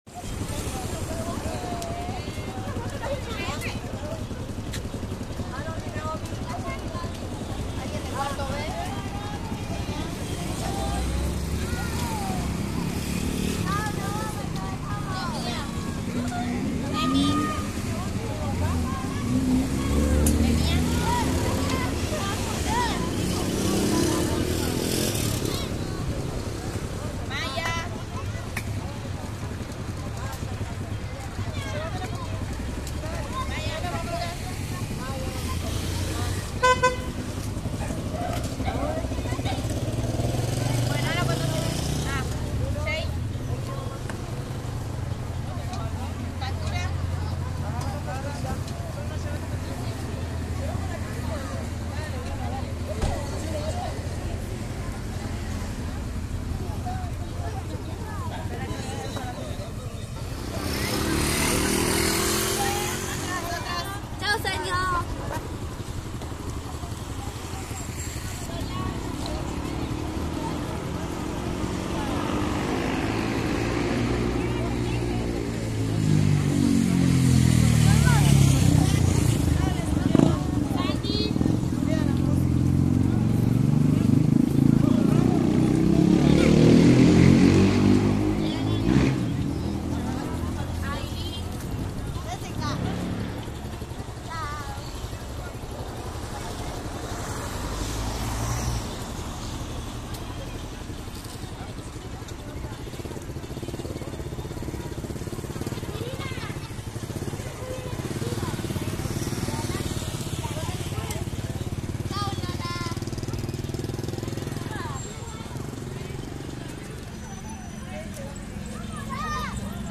Calle 5 de Agosto 1750 - Sonidos de Rosario
Ciudad de Rosario 17.30 hs. 26 de Agosto 2025